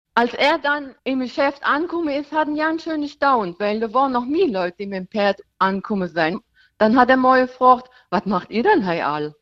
Mit Moselfränkisch